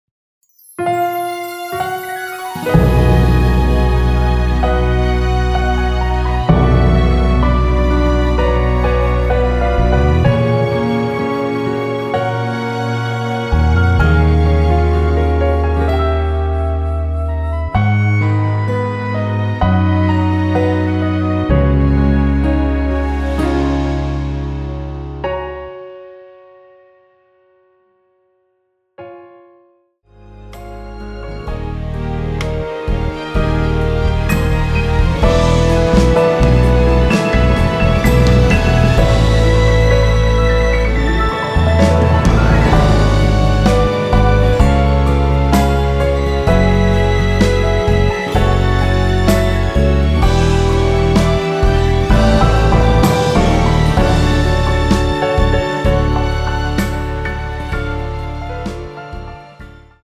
여성분이 부르실수 있는 키로 제작 하였습니다.(미리듣기 참조)
F#
앞부분30초, 뒷부분30초씩 편집해서 올려 드리고 있습니다.
중간에 음이 끈어지고 다시 나오는 이유는